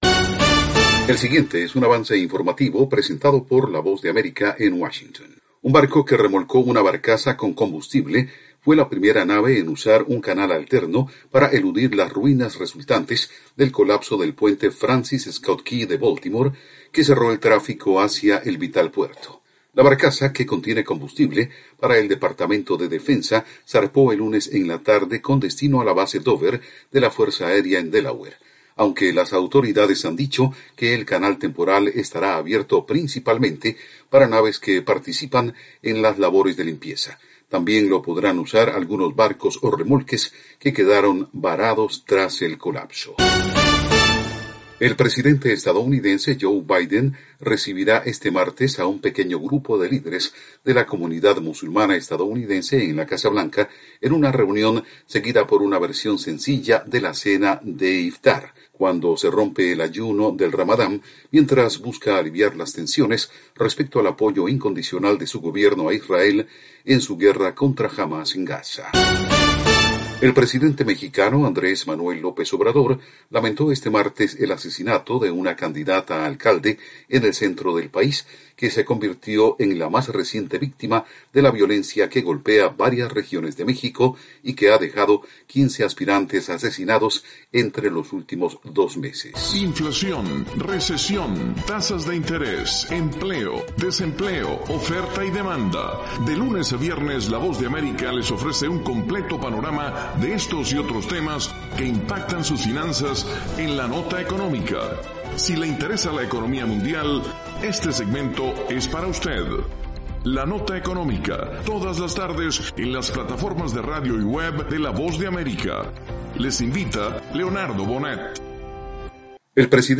El siguiente es un avance informative presentado por la Voz de America en Washington